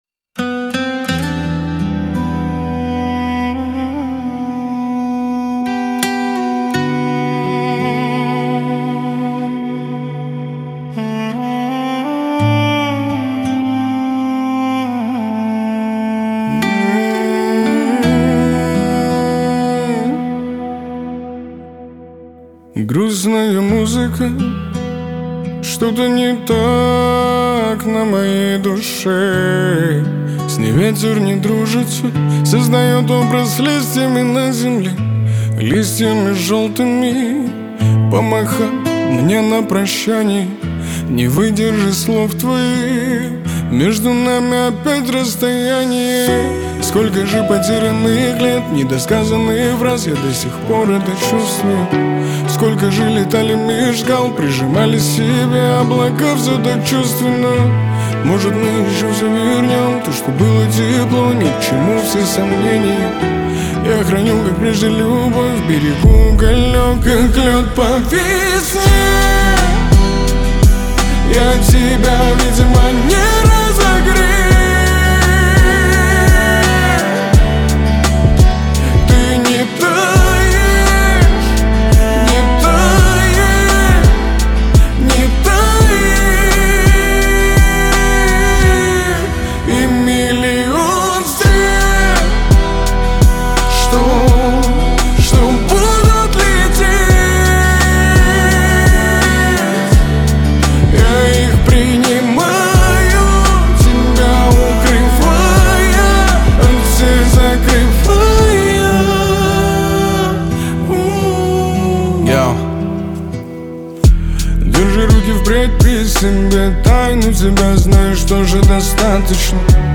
Грустная музыка